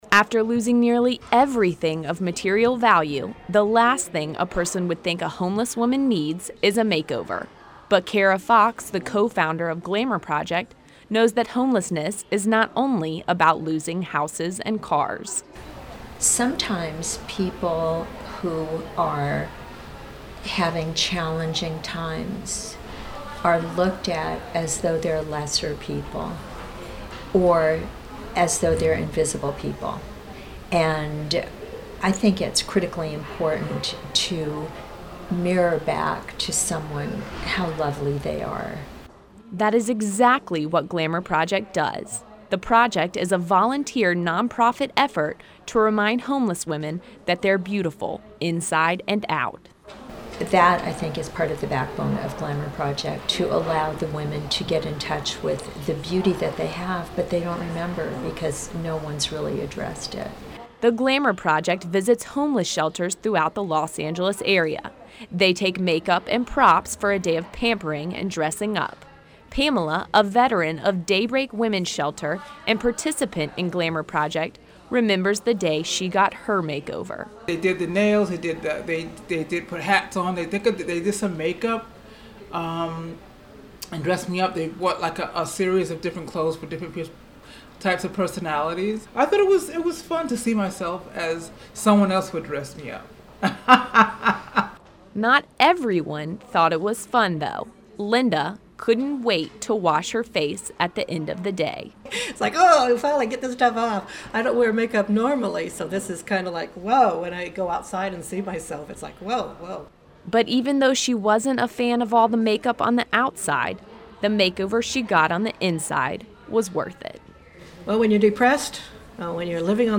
Glamour_Project_Feature.mp3